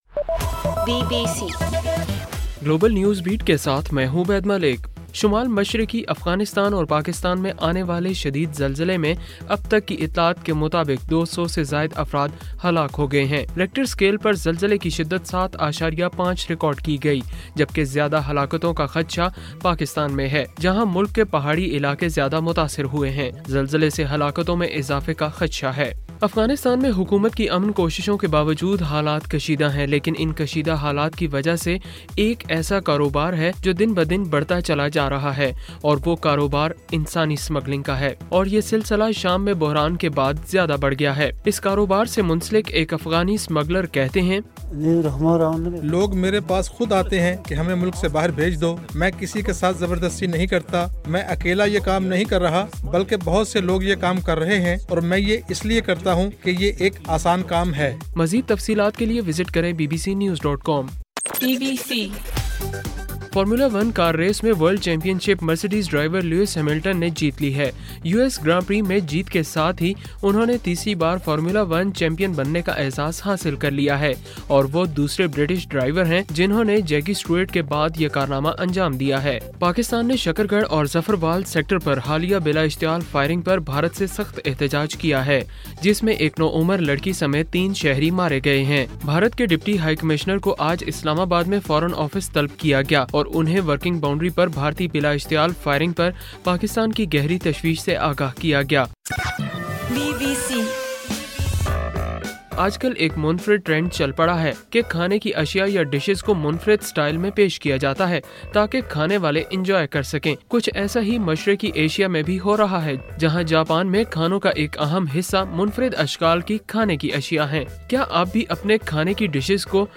اکتوبر 27:صبح 1 بجے کا گلوبل نیوز بیٹ بُلیٹن